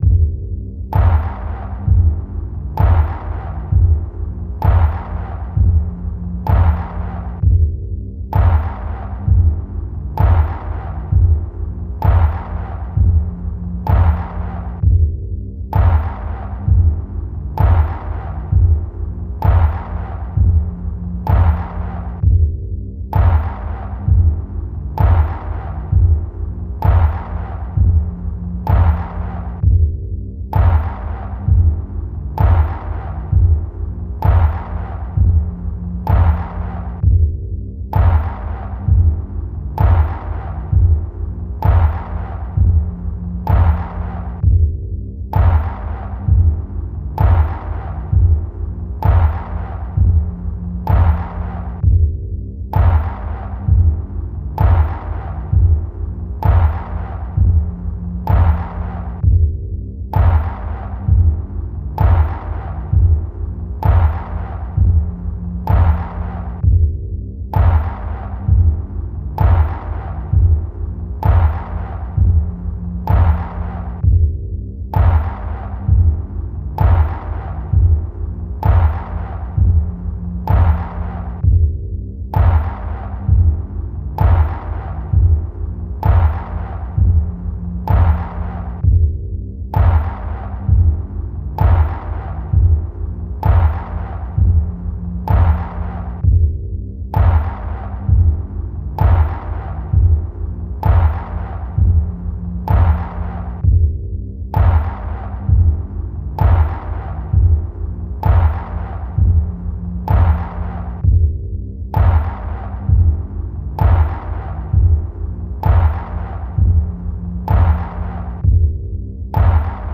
Hello , What is this effect that allows on IPAD PRO STEREO, to hear in addition to the stéréo, sounds that come from behind you, on left or on the right, when you are in front your IPAD WITHOUT HEADPHONES ?????